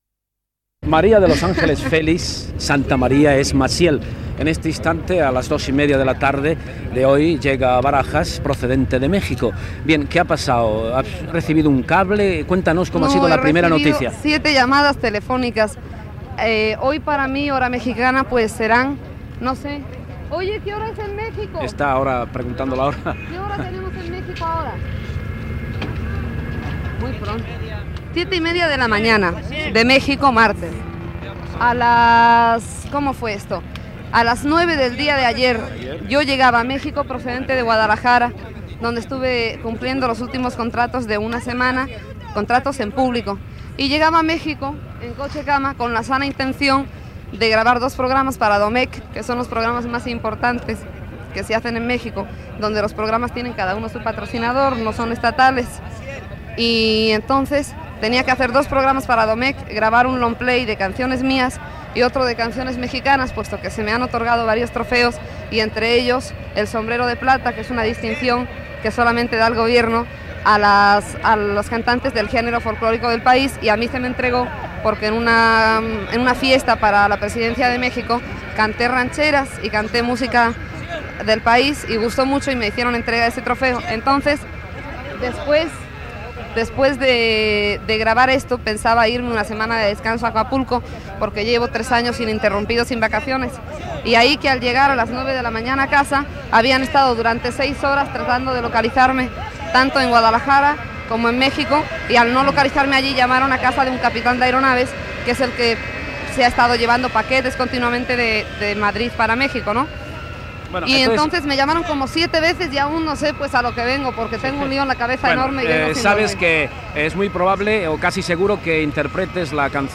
Entrevista a la cantant Massiel (María de los Ángeles Félix Santamaría ) quan arriba a l'aeroport de Barajas, procedent e Mèxic, per participar deu dies després al Festival d'Eurovisió en lloc del cantant Juan Manuel Serrat que no interpretarà finalment la cançó "La,la,la"
Informatiu